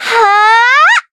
Taily-Vox_Casting5.wav